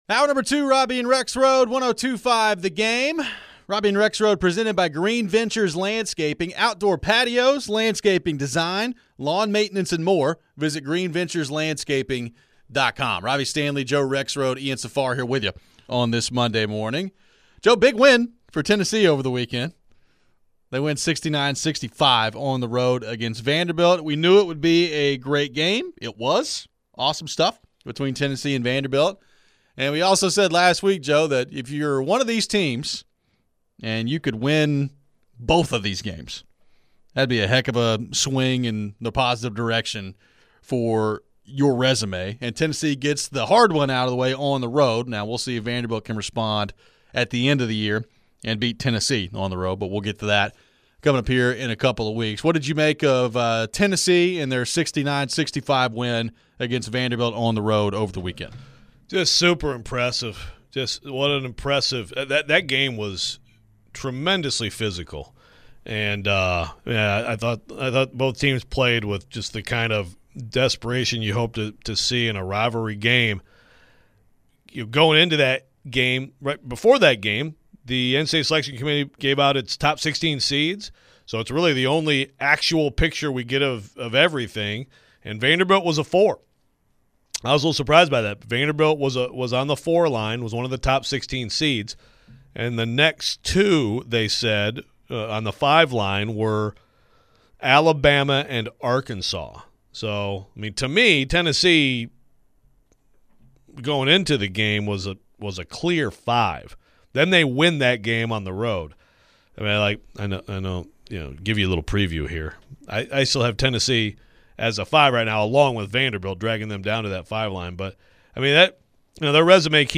We get back into some college hoops from the weekend and take your phones.